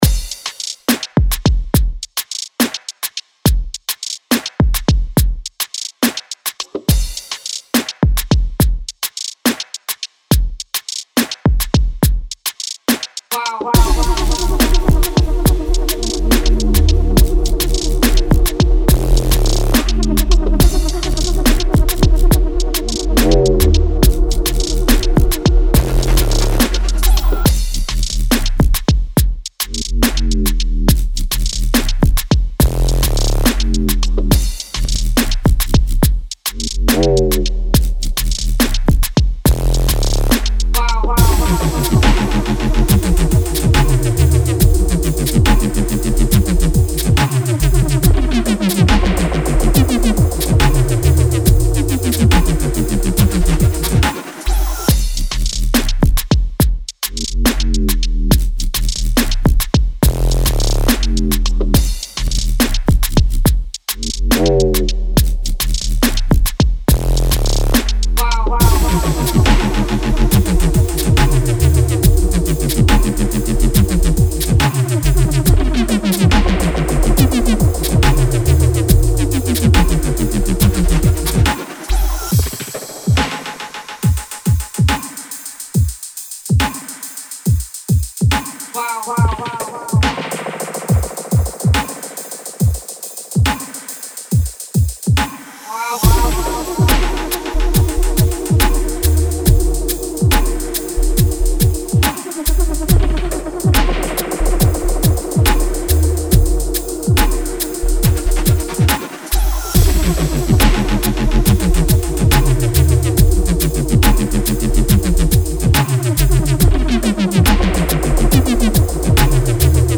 Genre Booty Bass